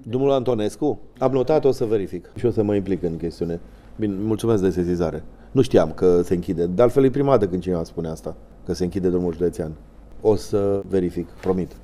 Președintele Consiliului Județean Cluj, Alin Tișe, nu știa că firmele de deszăpezire nu intervin în partea de serpentine a acestei șosele și le promite localnicilor că se va implica, în așa fel încât drumul să rămână deschis tot timpul anului.